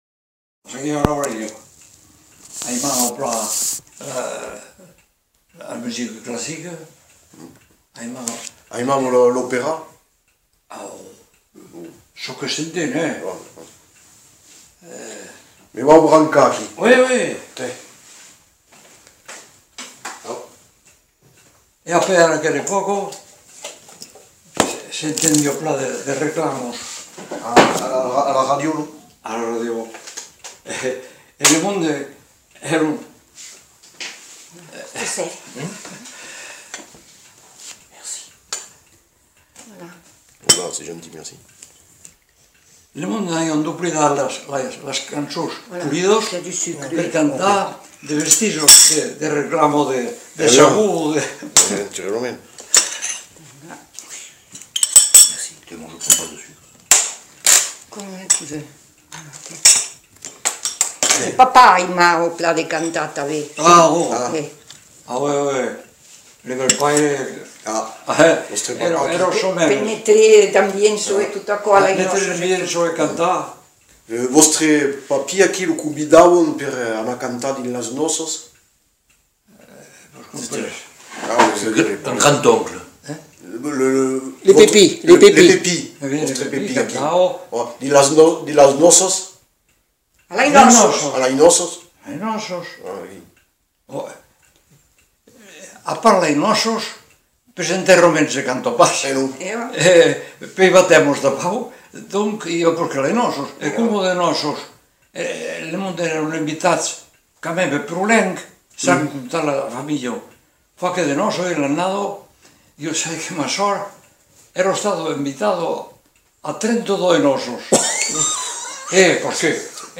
Discussion de début d'enquête